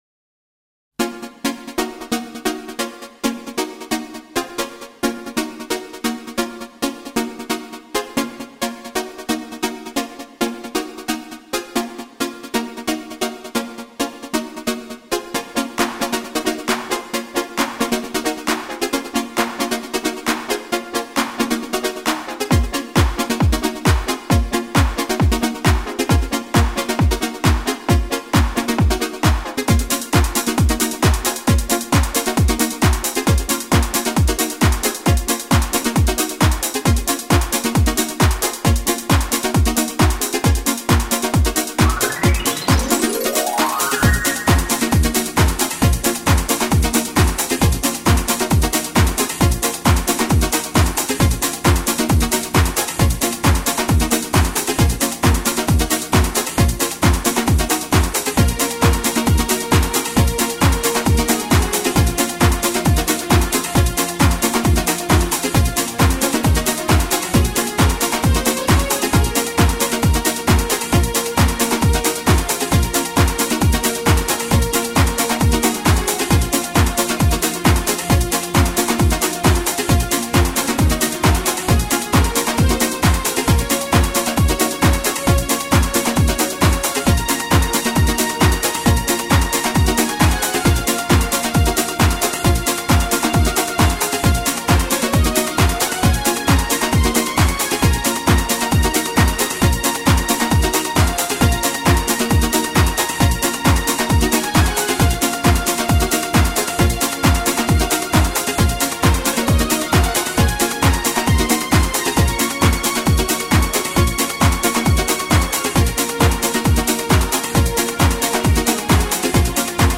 〽 ژانر بی کلام